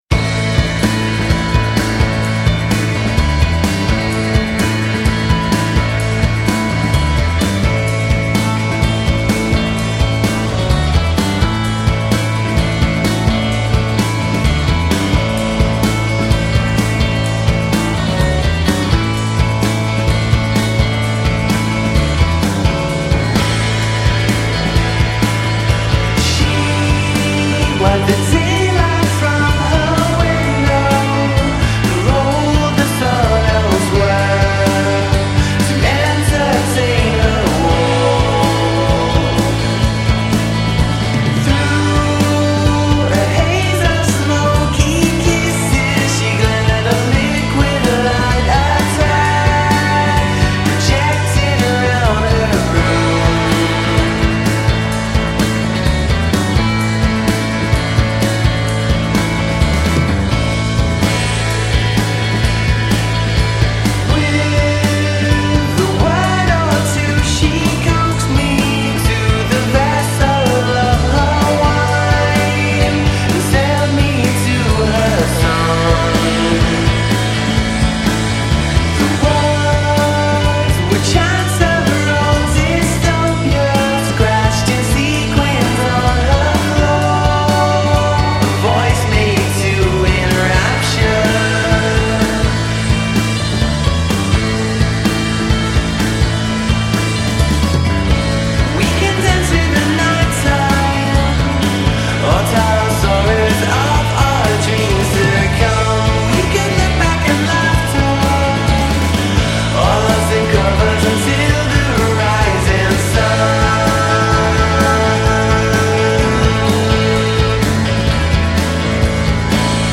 Neo-psychedelic quartet
woozy, off-kilter rock